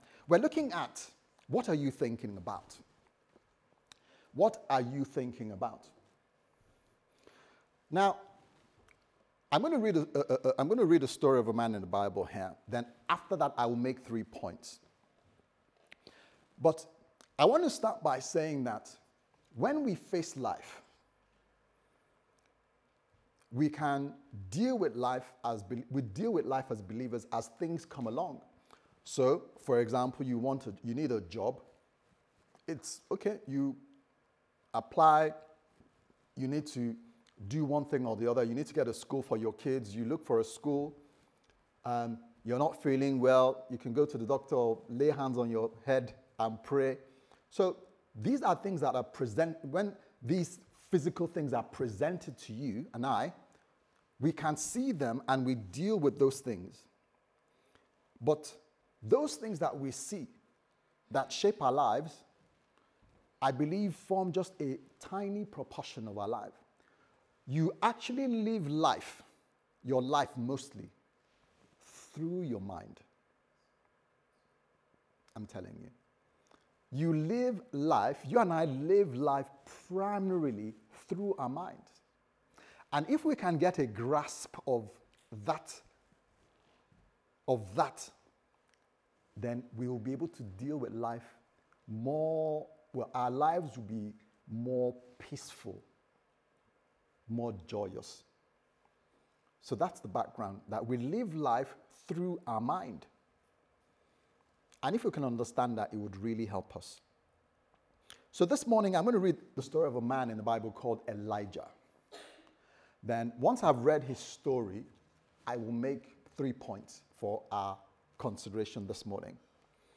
What Are You Thinking About Service Type: Sunday Service Sermon « Loving The Right Way